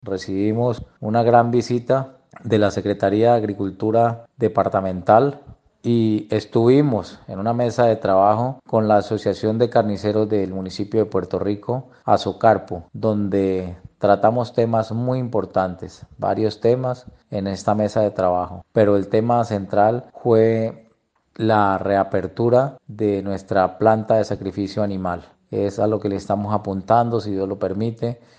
Robinson Anzola, alcalde del municipio de Puerto Rico, explicó que, esta sería una muy buena noticia para los habitantes de la región, especialmente en materia sanitaría, debido a que en la actualidad, los lugares donde se produce el desposte de la carné no son los más idóneos.
ALCALDE_ROBINSON_ANZOLA_MATADERO_-_copia.mp3